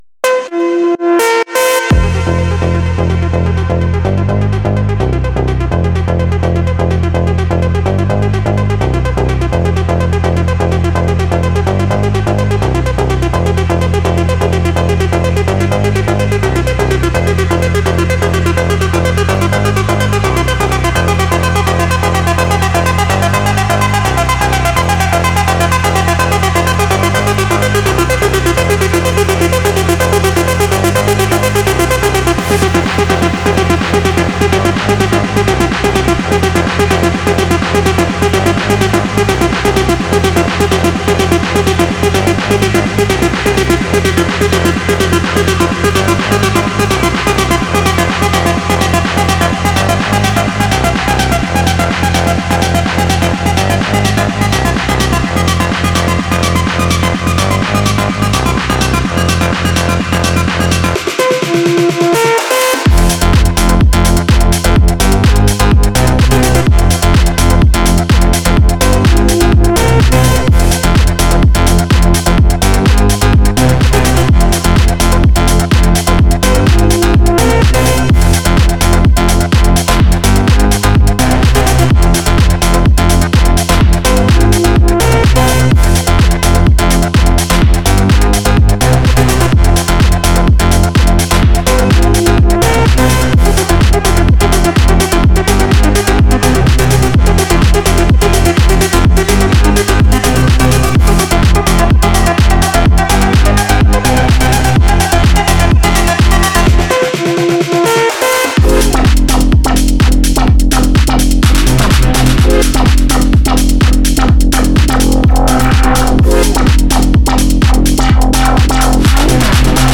мощным басом и динамичными ритмами